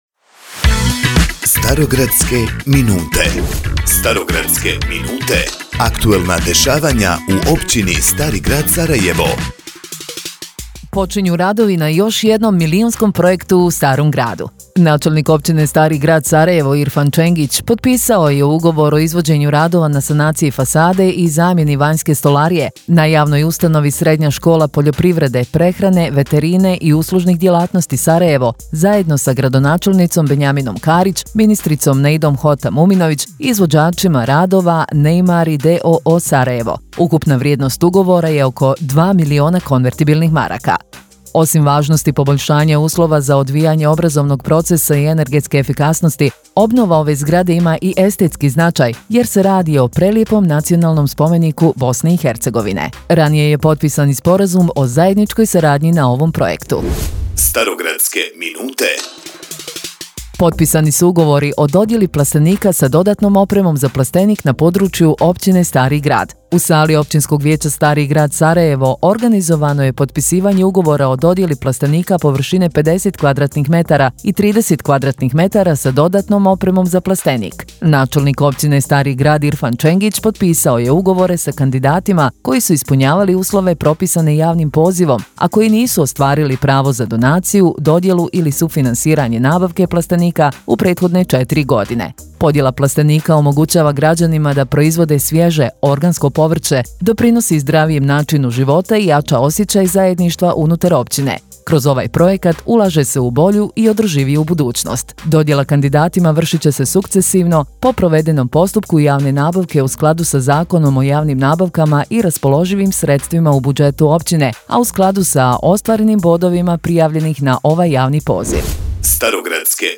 Partnerski Radio HIT FM Sarajevo realizuje posebnu informativnu radio emisiju o aktivnostima Općine Stari Grad i općinskog načelnika Irfana Čengića.